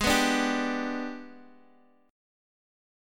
G/Ab Chord
G-Major-Ab-x,x,6,4,3,3.m4a